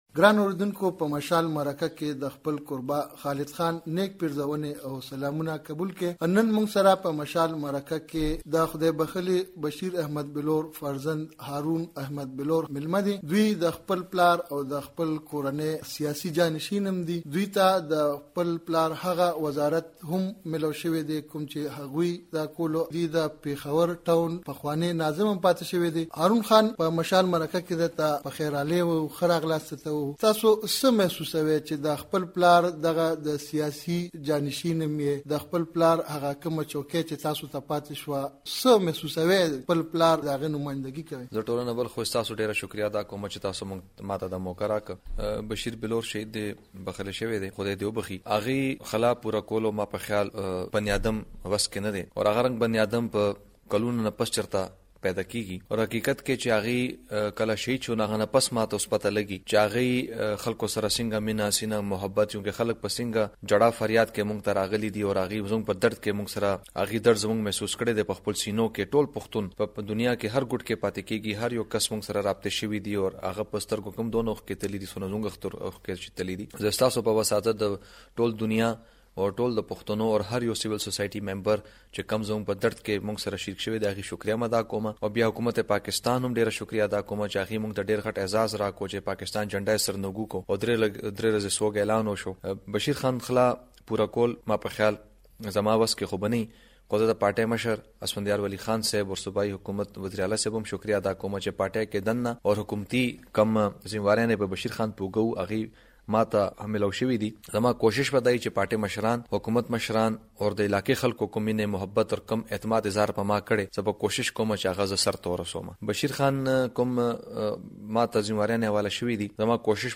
دهارون بلور سره مرکه